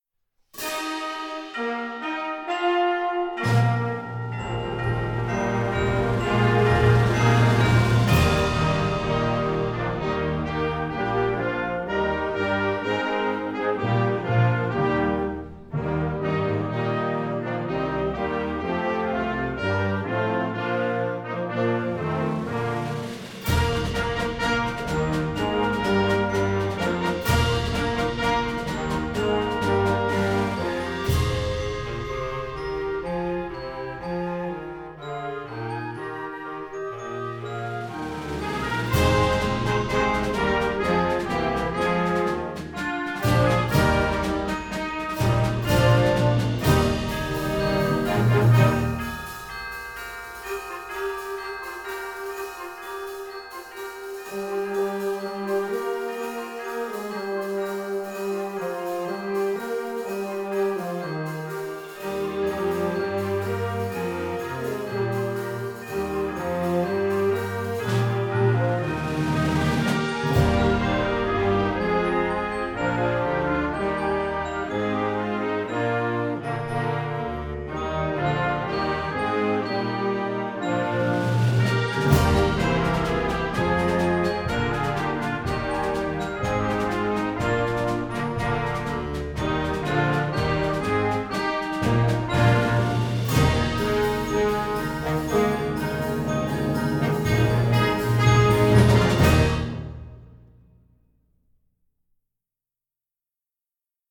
Gattung: Weihnachtslieder für Jugendblasorchester
Besetzung: Blasorchester